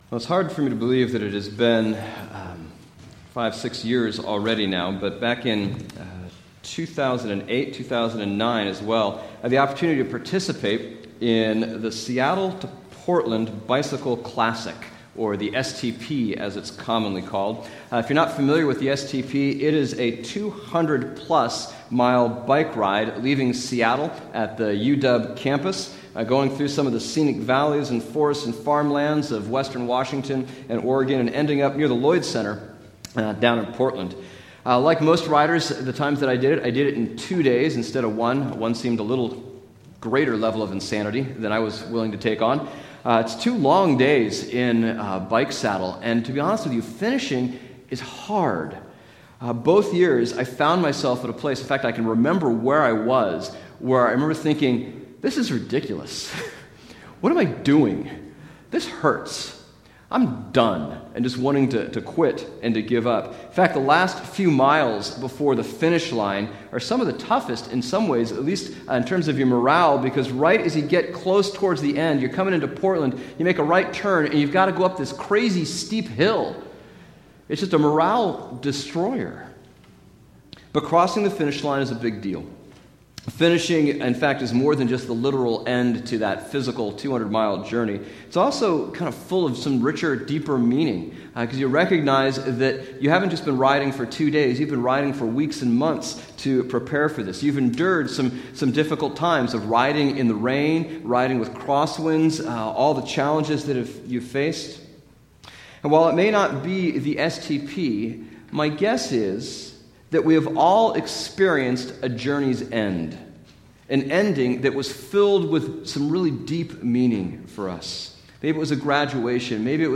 Bible Text: Luke 19:28-21:4 | Preacher: